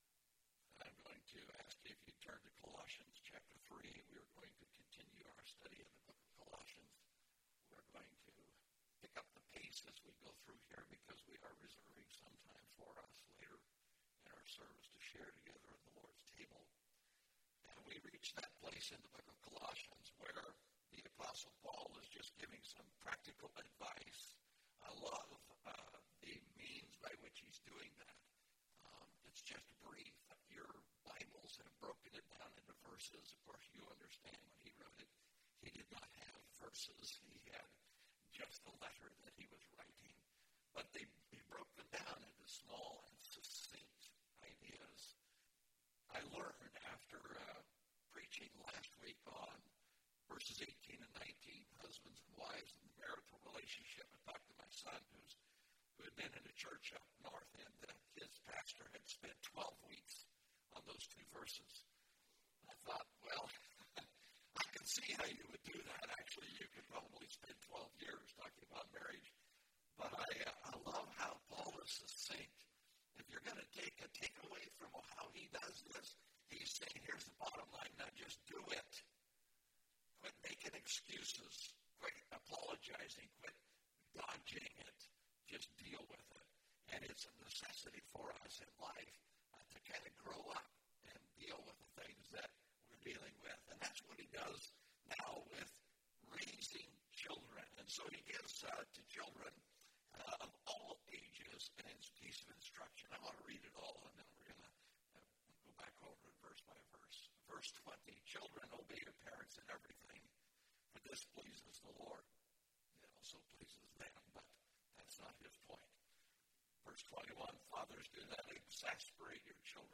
Love Leads (Colossians 3:20-4:1) – Mountain View Baptist Church